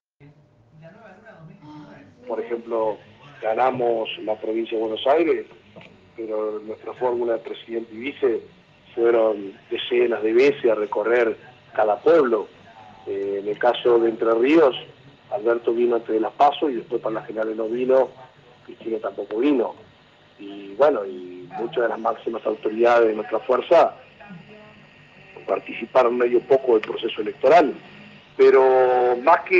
Un audio ya viralizado da vueltas por los whastapp de dirigentes, militantes y el periodismo, en el mismo, con total nitidez se puede escuchar la inconfundible voz del presidente del IAPV y diputado nacional electo, Marcelo Casaretto.